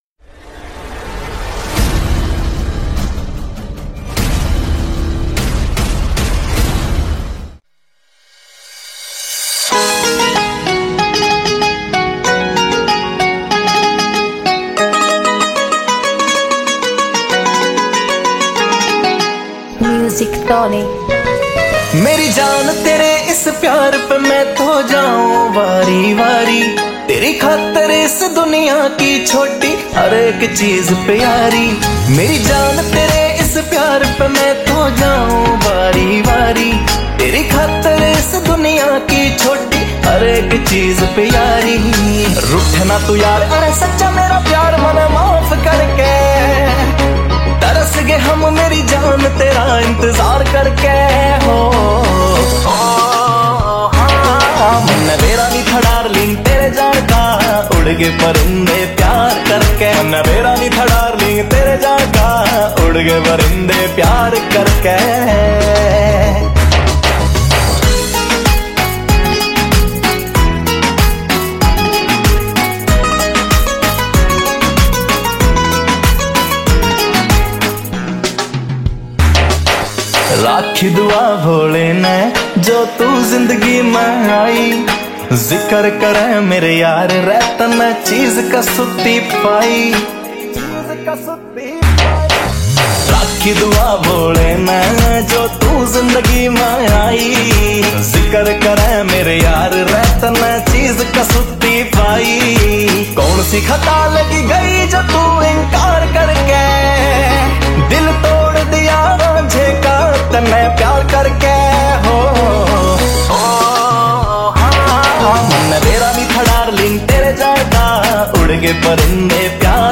Category New Haryanvi Song 2023